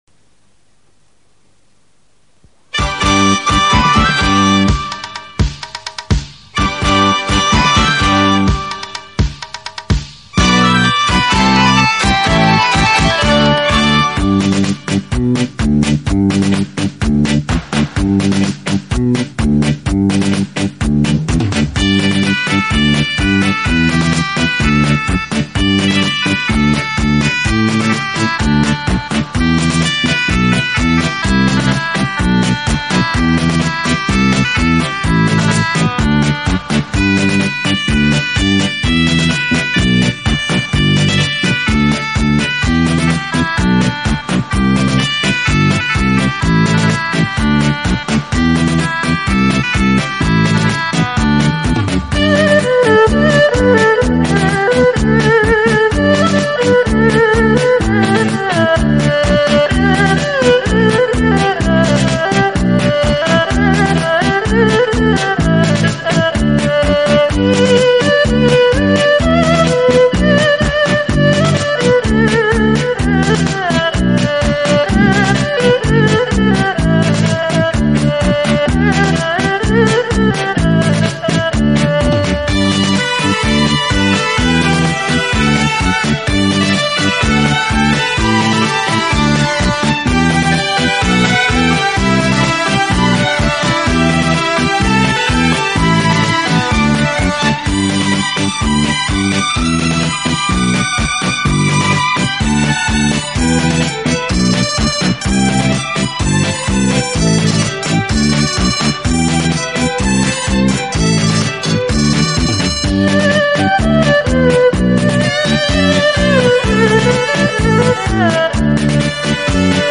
本专辑主要以优美的浙江小调歌曲为主编成舞曲，舞曲旋律流畅 动听 节奏感强，是不可多得的舞曲专辑
伦巴